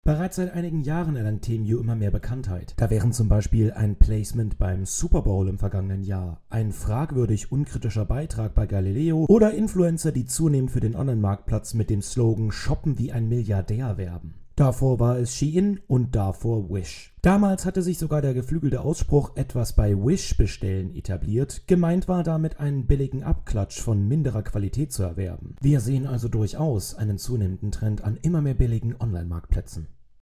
Er ist Experte für Psychologie im Marketing.